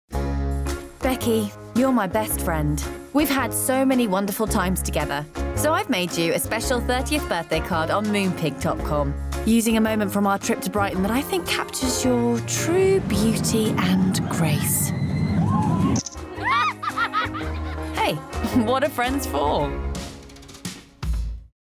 Smooth, Warm and Natural to Light, Friendly and Conversational. 20-30.
Commercial, Conversational, Natural, Relaxed
Straight